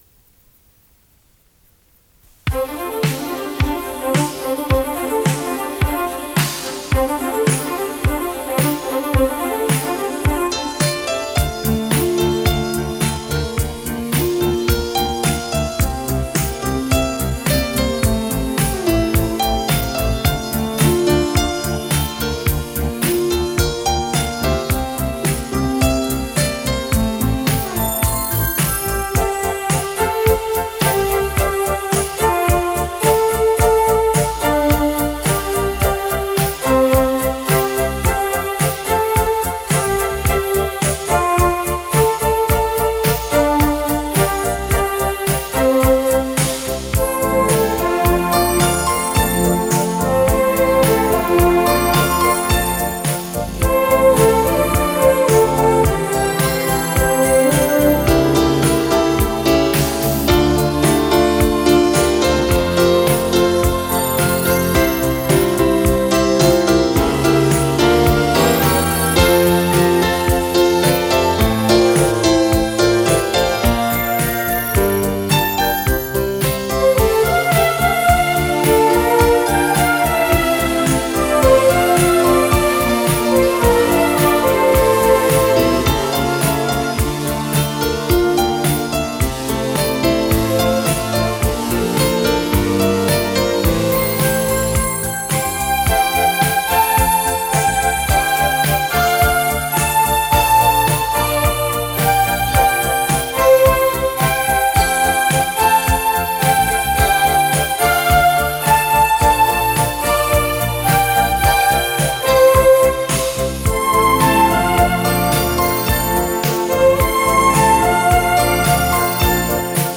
曲子里又添加了不少法国的浪漫风格